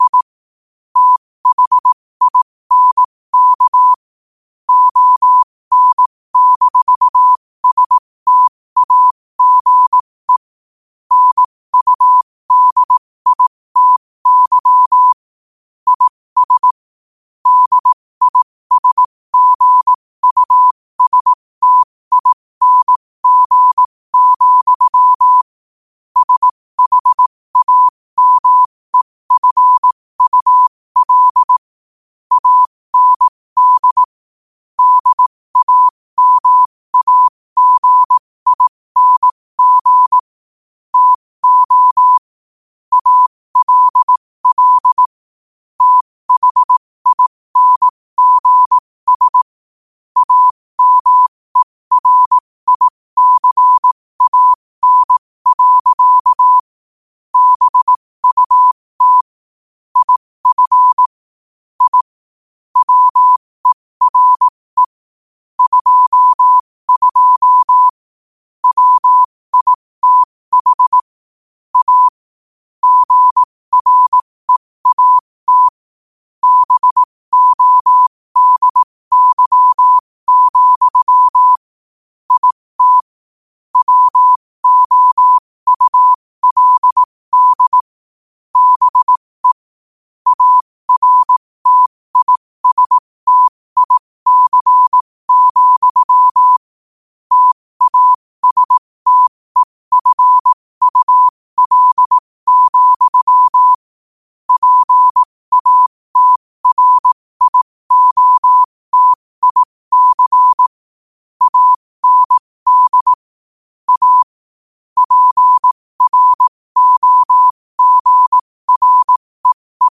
New quotes every day in morse code at 15 Words per minute.